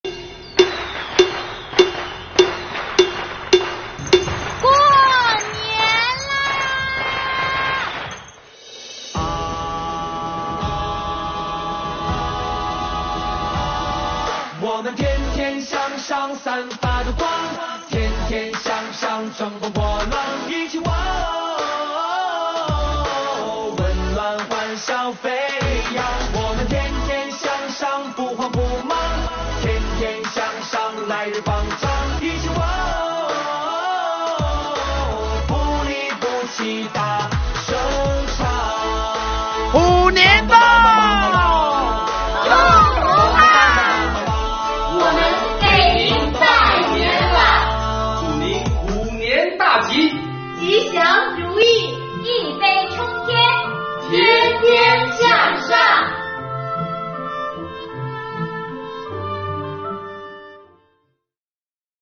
值此新春佳节之际广西各地税务干部用短视频向您送来最诚挚的新春祝福。